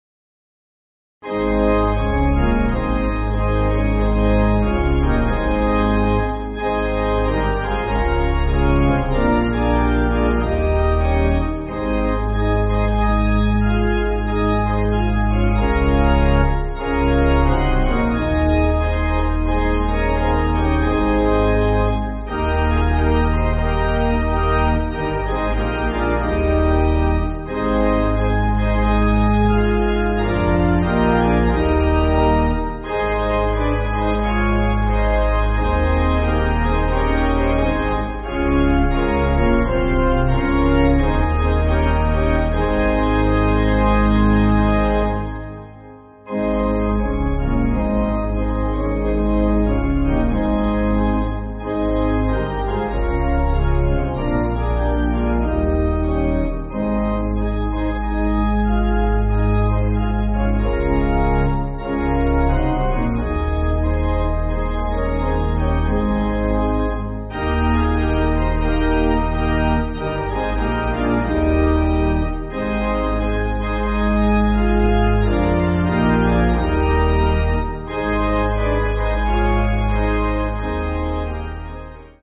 (CM)   3/Ab